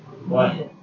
speech
speech-commands